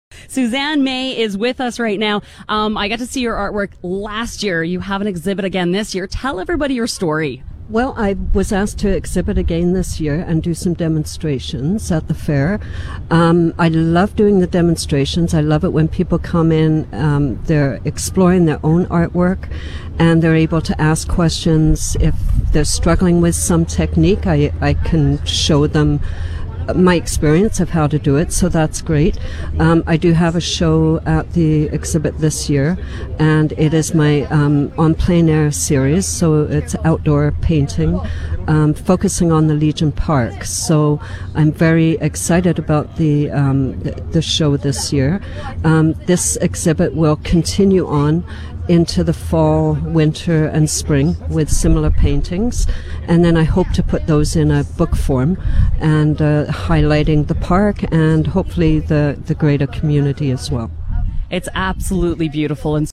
I got to interview that very artist on the radio!